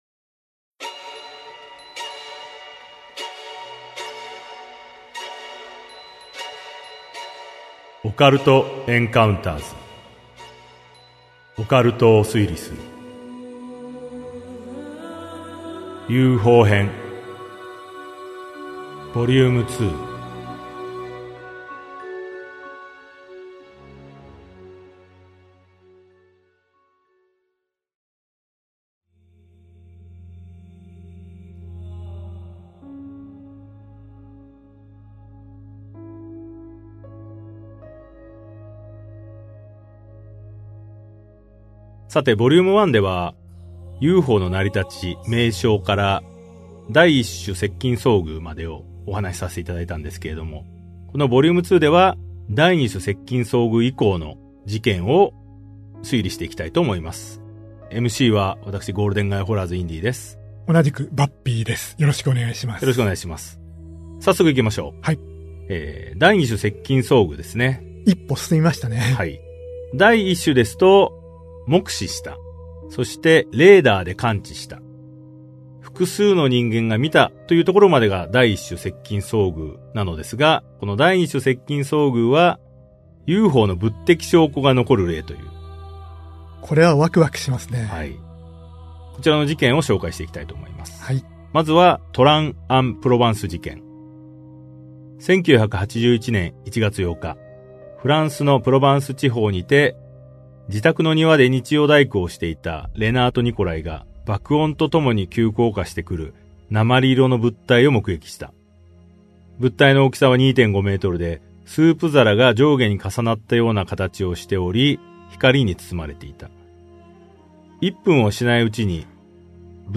[オーディオブック] オカルト・エンカウンターズ オカルトを推理する Vol.02 UFO編2